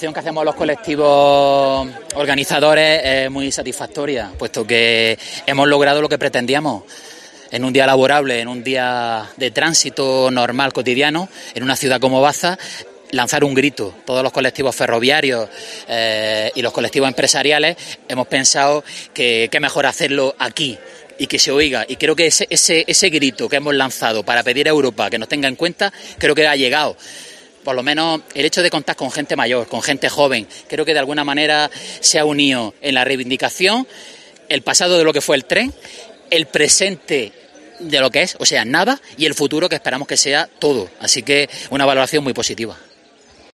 Concentración a favor del ferrocarril en Baza